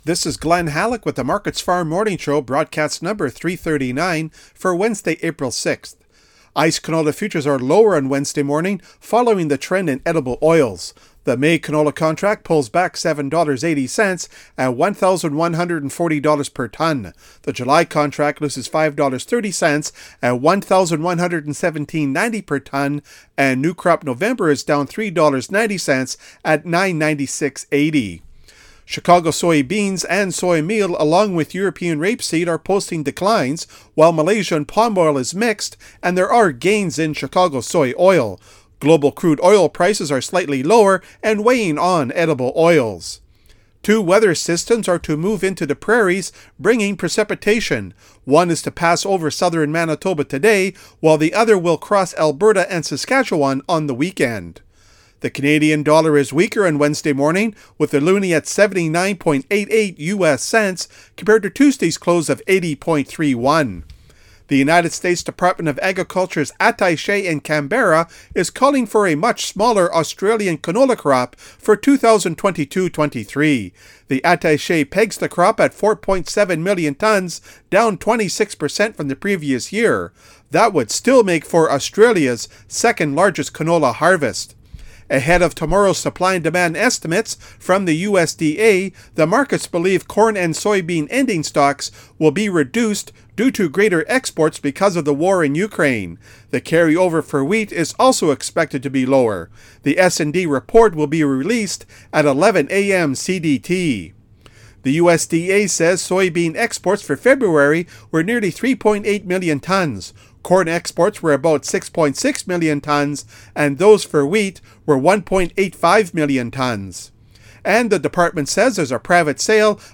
MarketsFarm Moring Radio Show April 6 - AgCanada